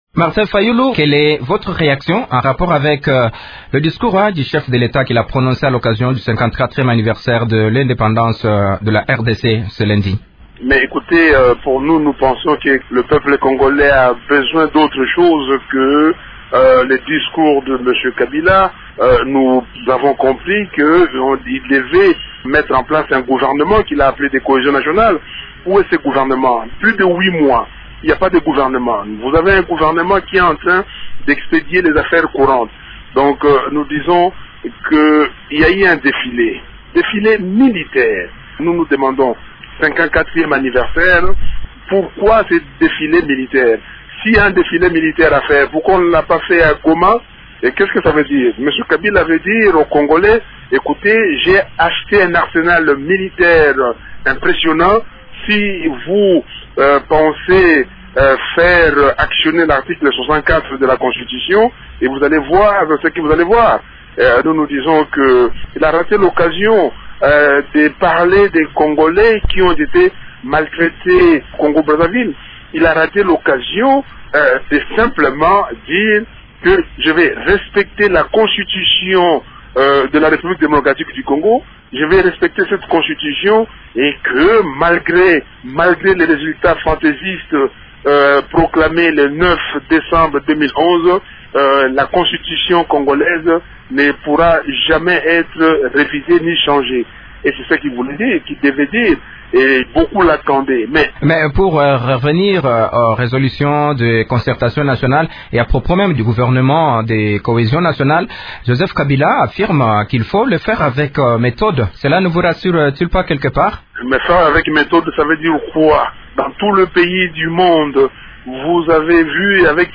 Le président du parti politique de l’opposition Ecide et membre de la plate forme « Sauvons la RDC » est l’invité de Radio Okapi ce mercredi. Il réagit au discours du président de la République prononcé dimanche à l’occasion du 54e anniversaire de l’indépendance de la RDC.